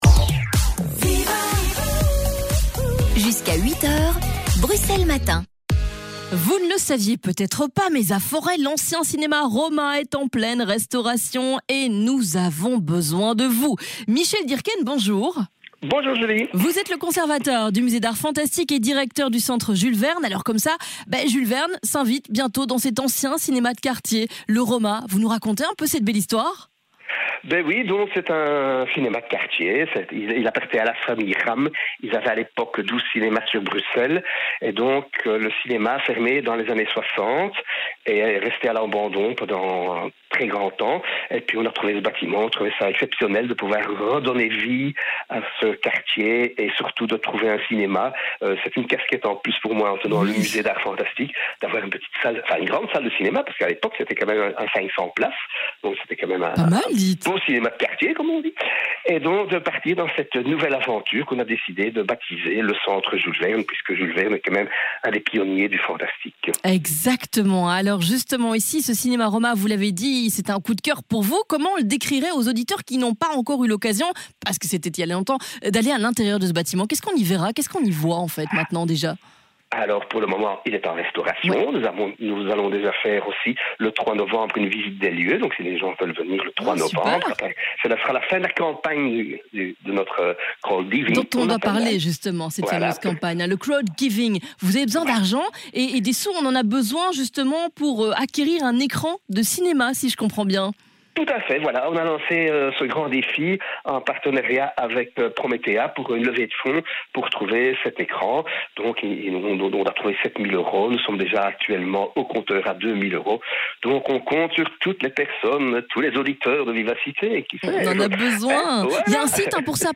ITW_Vivacite_CJV_24.mp3